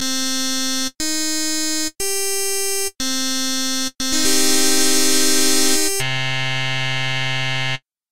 Once you’re done you can play your sample like any other instrument.